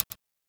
Click (14).wav